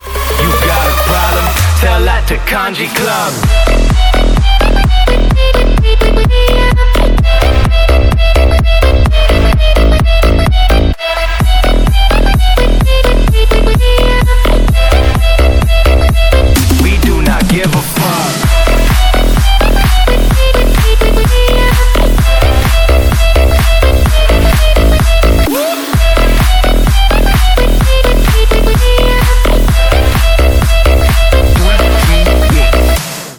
• Качество: 128, Stereo
громкие
мощные
заводные
EDM
энергичные
Big Room
клубняк
electro house
Future Bounce